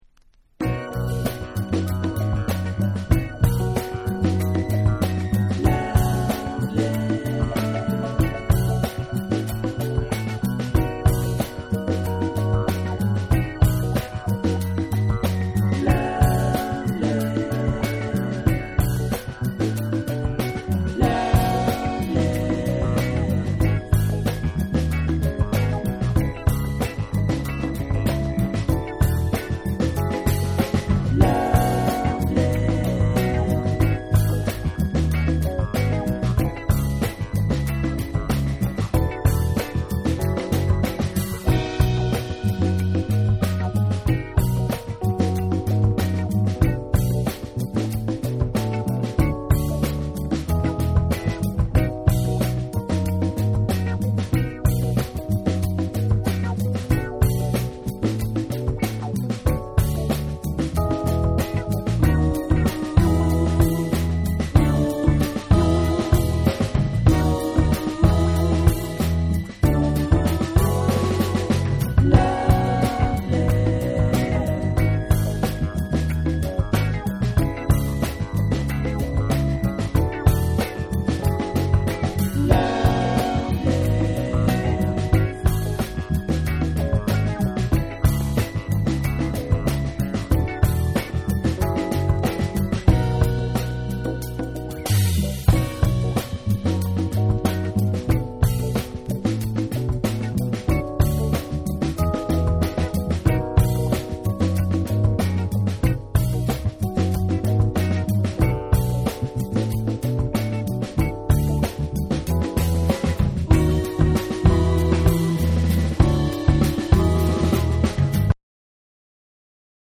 SOUL & FUNK & JAZZ & etc / BREAKBEATS